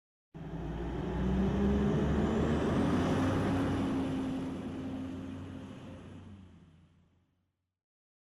bus_sound.mp3